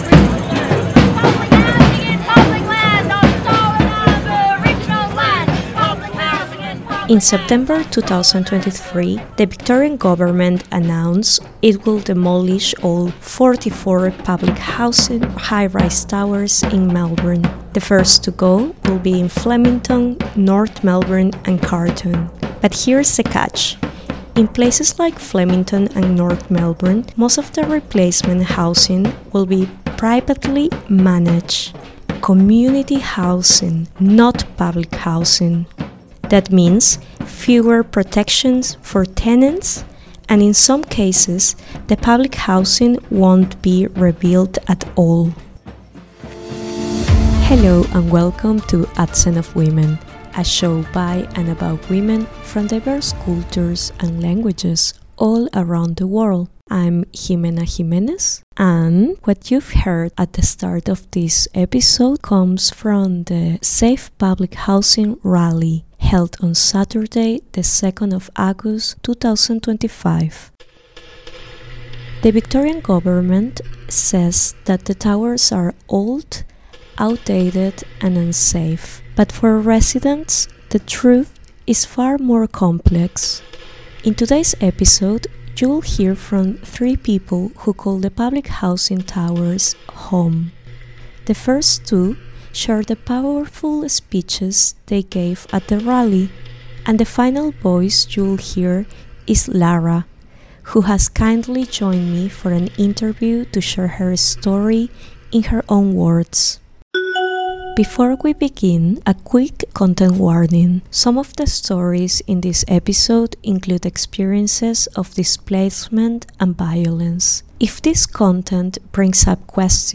Finally, we hear from the Melbourne Bergerak choir, sharing a series of resistance songs as well as poetry in Bahasa Indonesia.